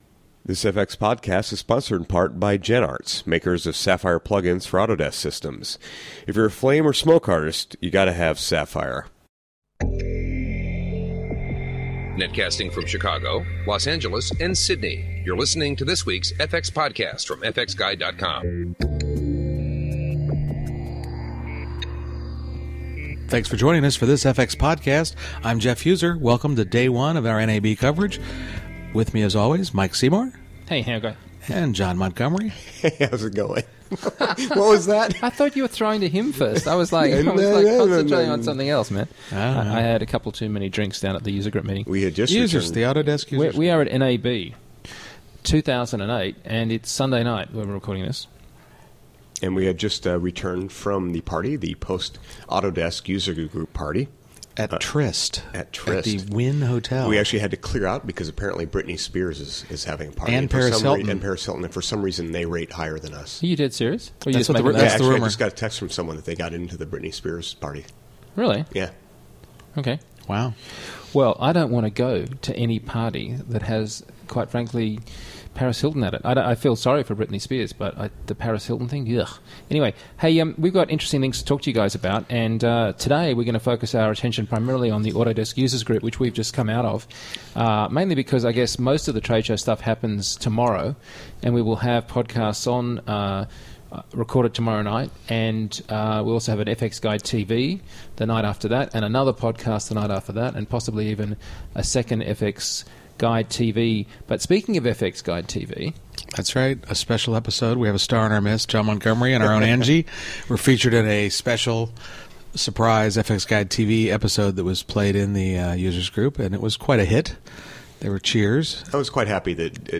Coverage of the Autodesk Usergroup Meeting.